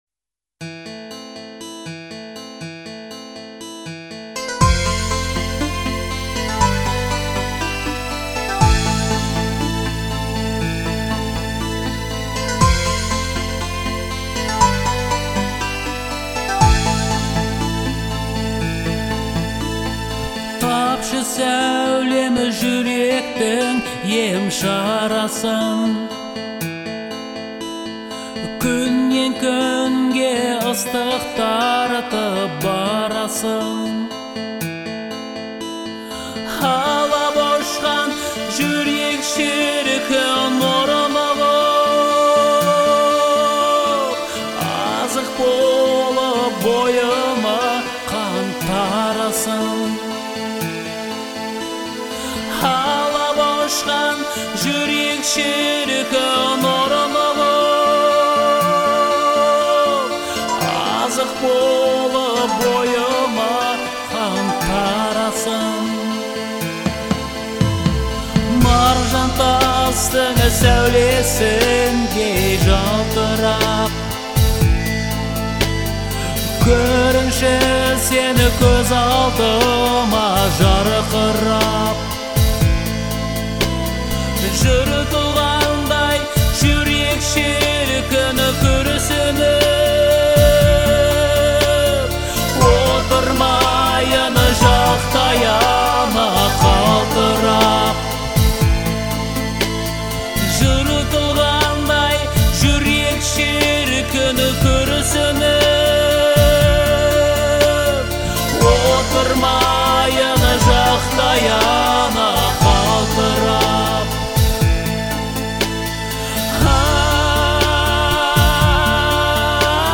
это романтическая песня в жанре казахского попа